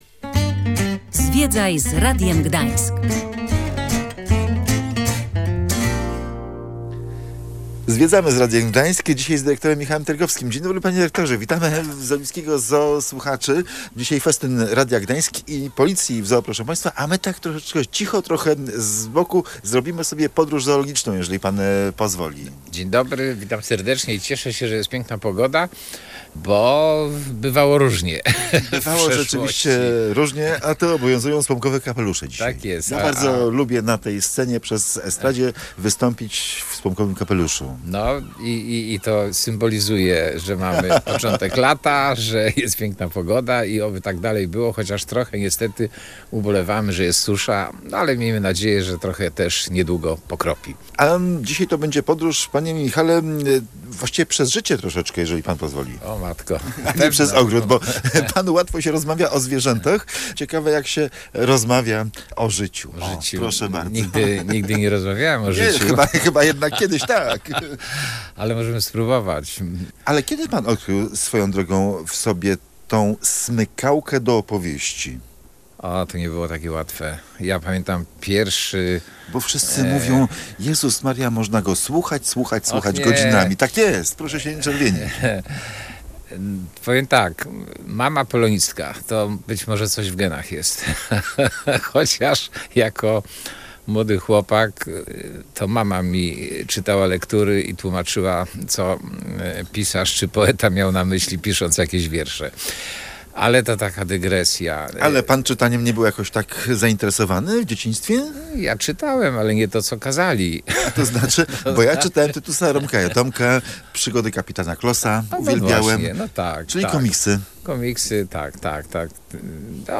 Dlatego w sobotę, 3 czerwca, w Gdańskim Ogrodzie Zoologicznym w Oliwie zorganizowaliśmy festyn rodzinny.